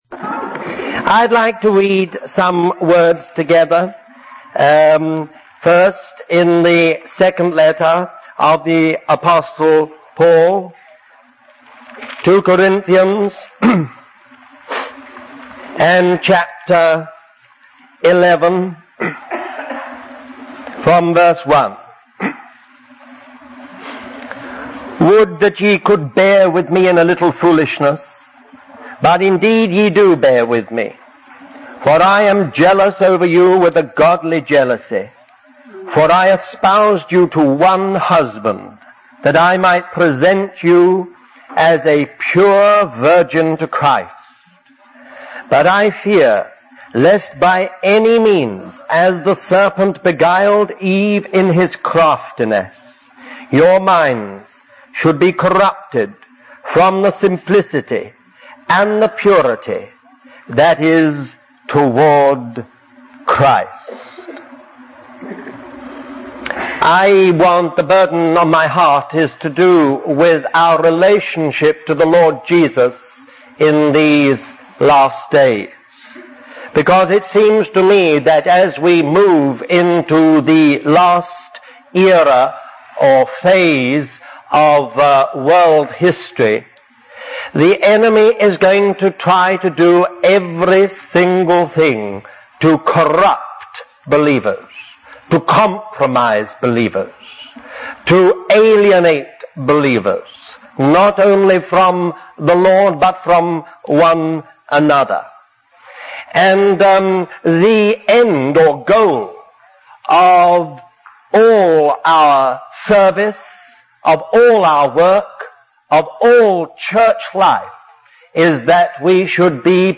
Christian Family Conference July 4, 1984